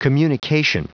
Prononciation du mot communication en anglais (fichier audio)
Prononciation du mot : communication